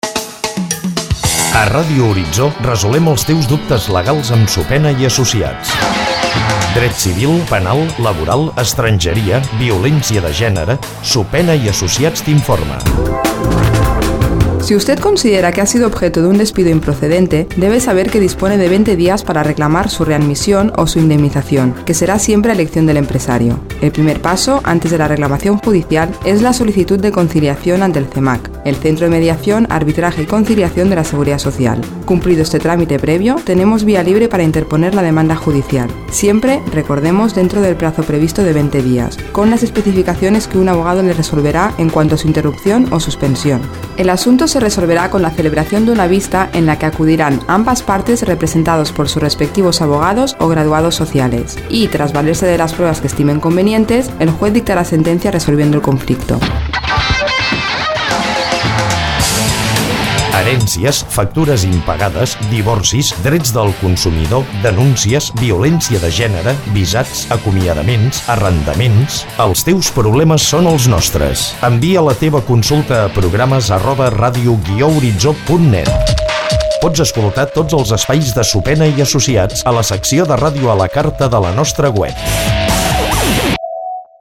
Careta del programa i informació sobre l'acomiadament improcedent
Divulgació